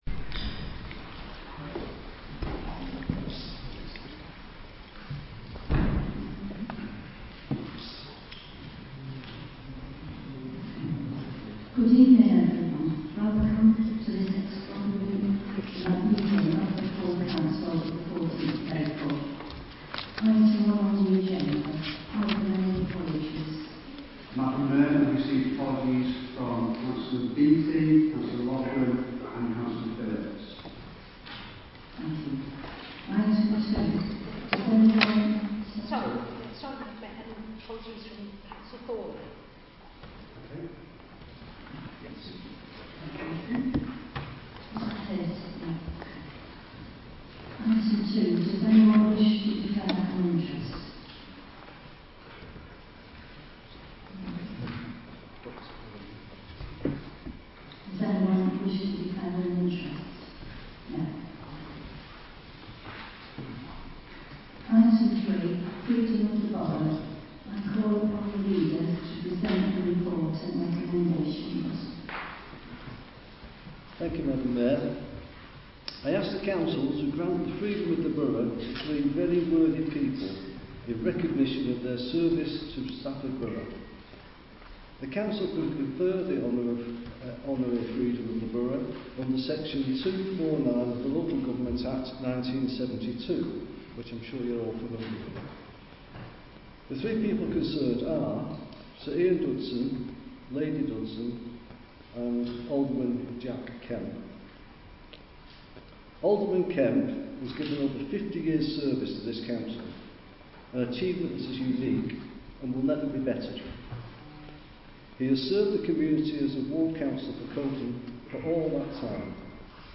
Committee: Council
Location: Council Chamber, County Buildings, Martin Street, Stafford